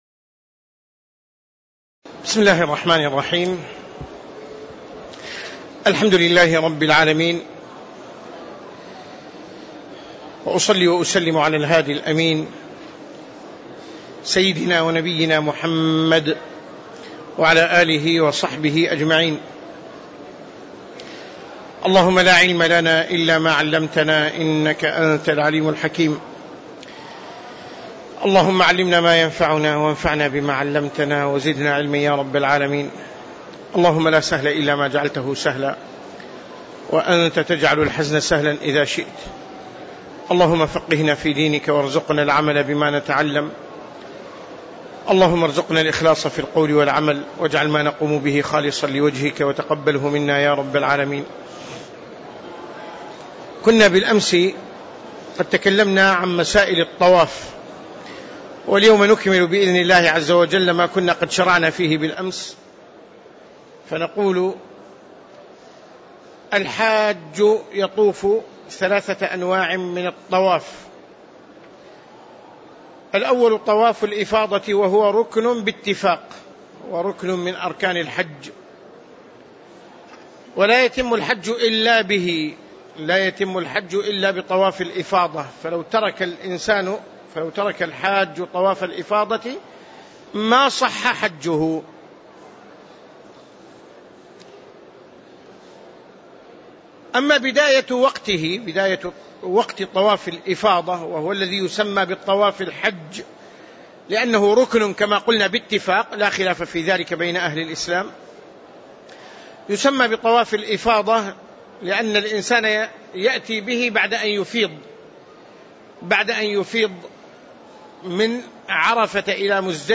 تاريخ النشر ١٨ ذو القعدة ١٤٣٧ هـ المكان: المسجد النبوي الشيخ